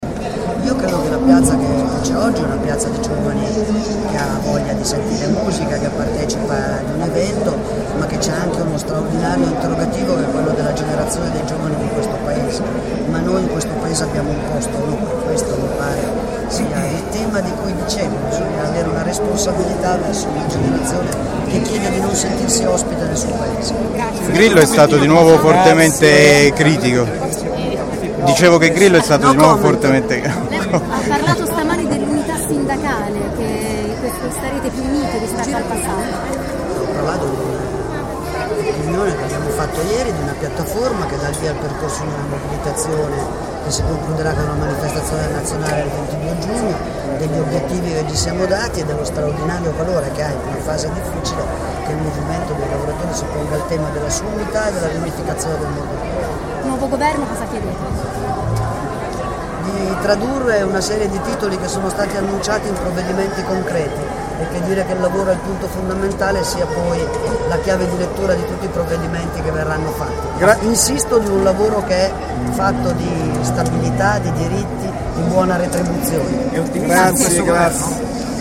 play_circle_filled Intervista a Susanna Camusso Radioweb C.A.G. di Rapallo Segretario nazionale sindacato CGIL intervista del 01/05/2013 La nostra TWR, presente al concerto del I° Maggio a Roma organizzato da sindacati congiunti, ha raccolto le parole del segretario nazionale della CGIL durante un intervista rilasciata a più network televisi e radiofonici.